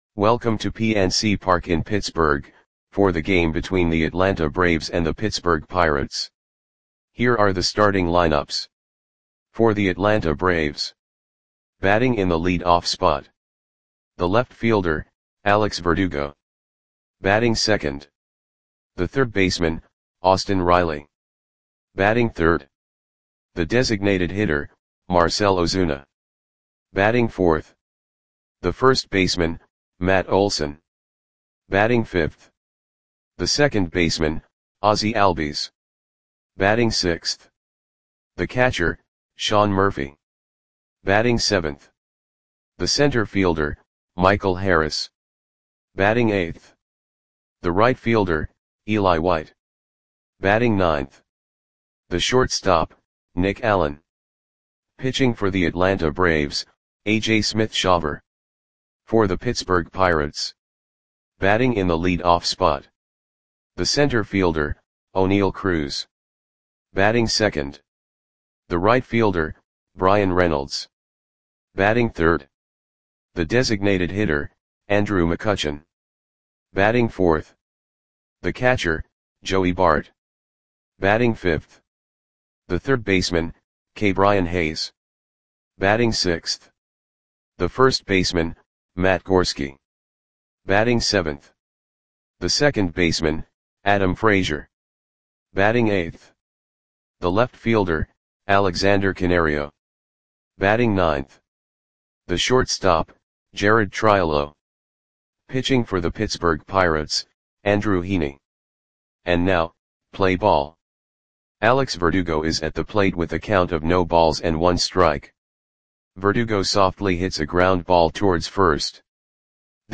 Audio Play-by-Play for Pittsburgh Pirates on May 10, 2025
Click the button below to listen to the audio play-by-play.